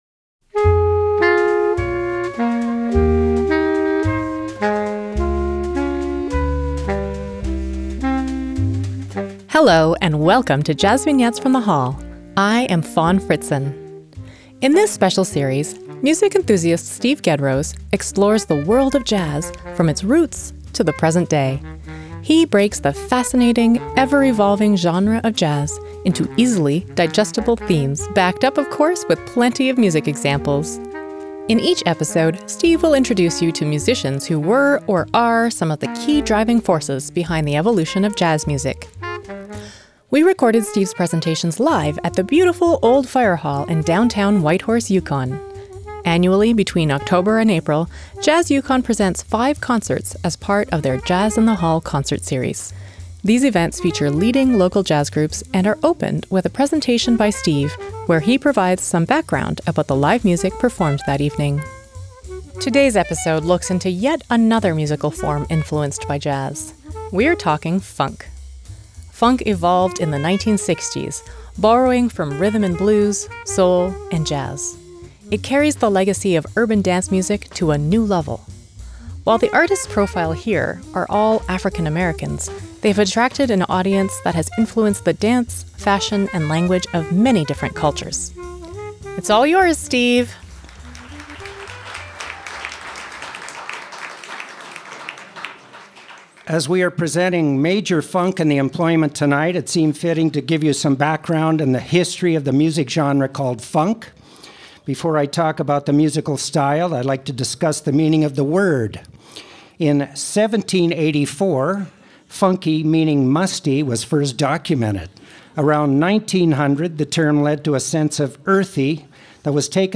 JVFTH_31_-_Funk.mp3